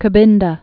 (kə-bĭndə)